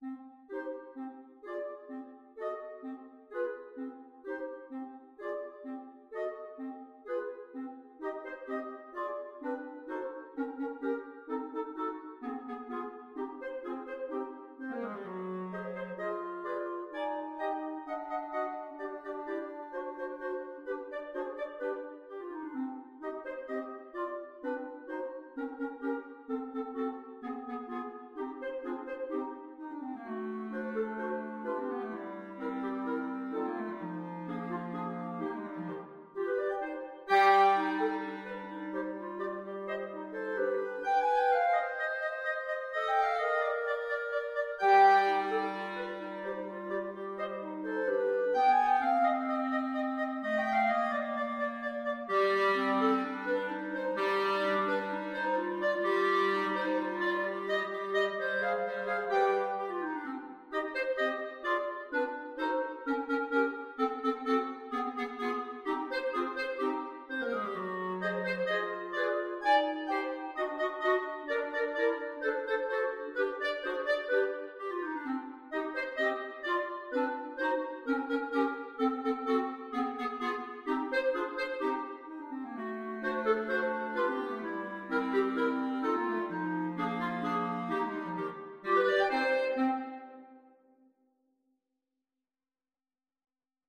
Free Sheet music for Clarinet Quartet
4/4 (View more 4/4 Music)
G minor (Sounding Pitch) A minor (Clarinet in Bb) (View more G minor Music for Clarinet Quartet )
Clarinet Quartet  (View more Intermediate Clarinet Quartet Music)
Classical (View more Classical Clarinet Quartet Music)